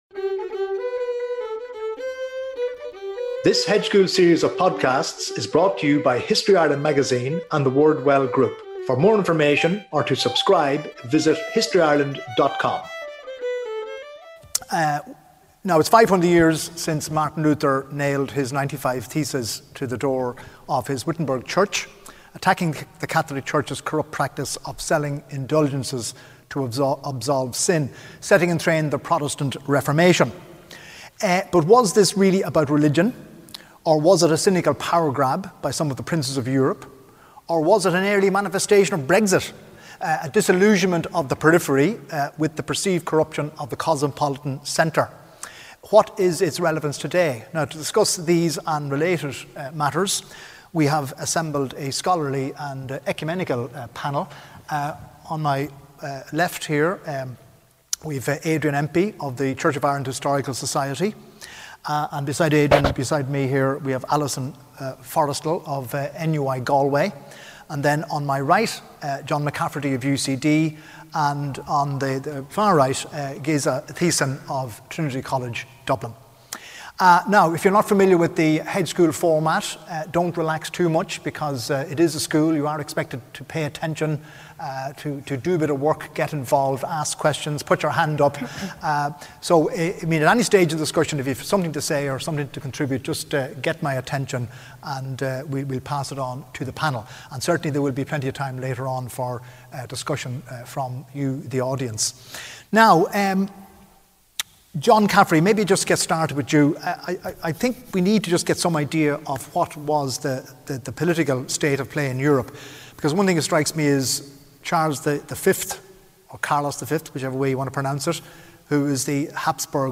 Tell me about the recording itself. @ St Werburgh’s Church, Werburgh Street. 7pm Wed 18 October 2017